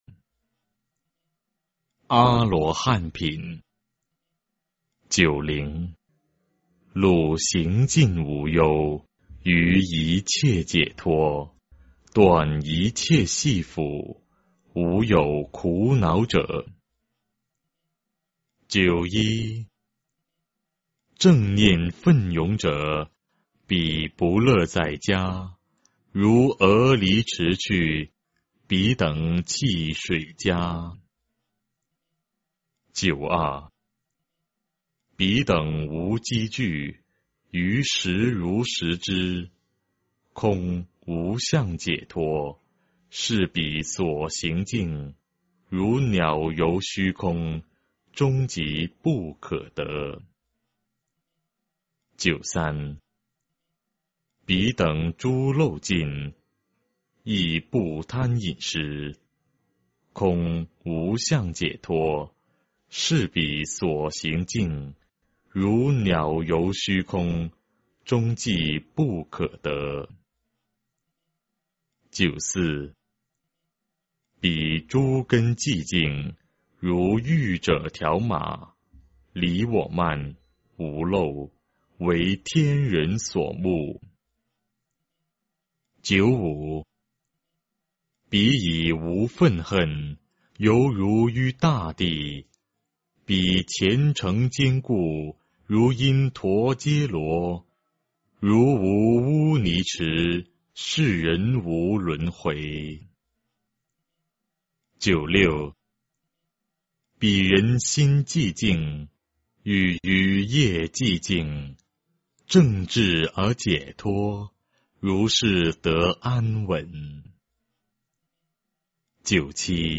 法句经-阿罗汉品（念诵）